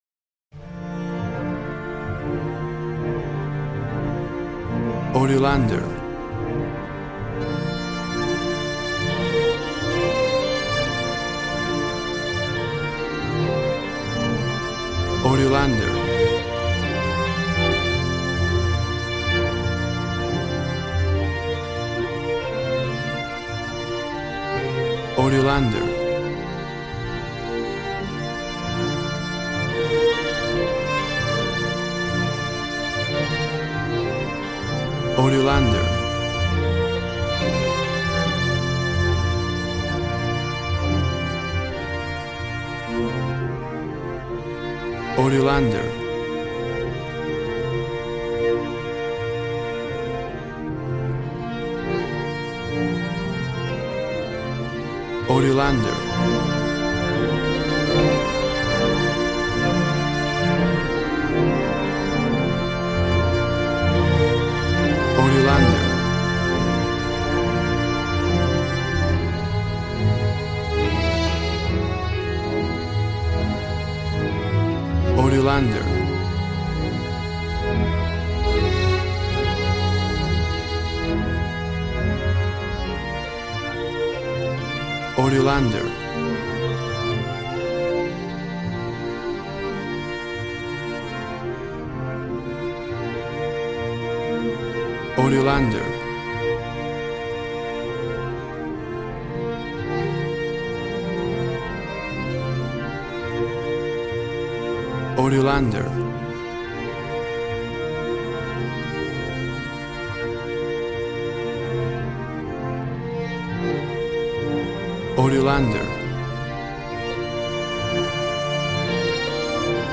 Tempo (BPM) 130